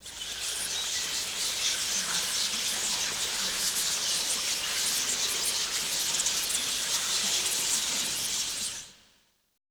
Index of /90_sSampleCDs/Best Service - Extended Classical Choir/Partition I/VOICE ATMOS
WHISPERING-L.wav